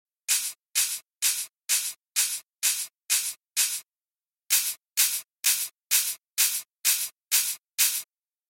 In this example I’ve used a very light bit crusher to add a little crunch and noise to the open hi hat.
Here’s a before and after: